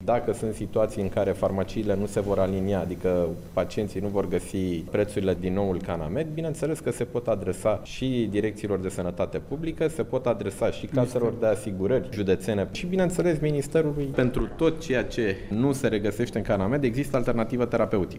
Astazi a fost publicat in Monitorul Oficial ordinul care reglementeaza aceasta compensare, iar seara vor ajunge la farmacisti noile preturi pentru fiecare medicament spune ministrul sanatatii Nicolae Banicioiu